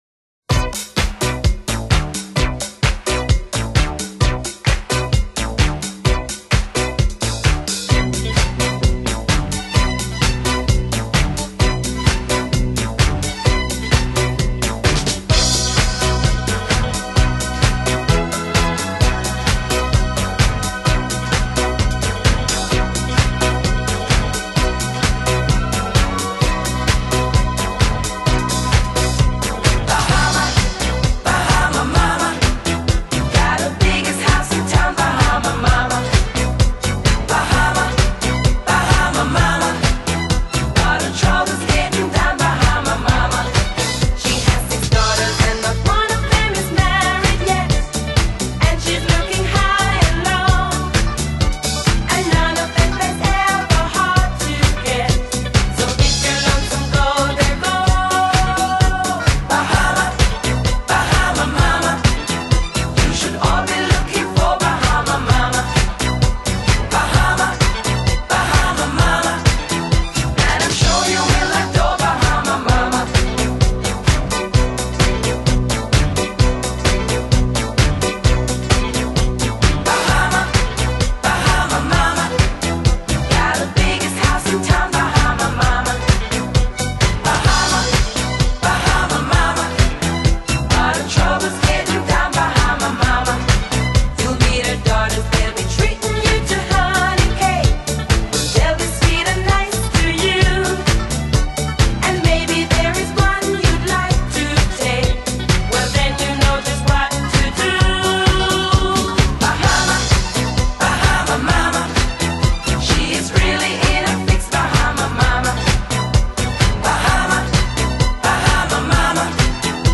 Genre: Pop, Dance, Disco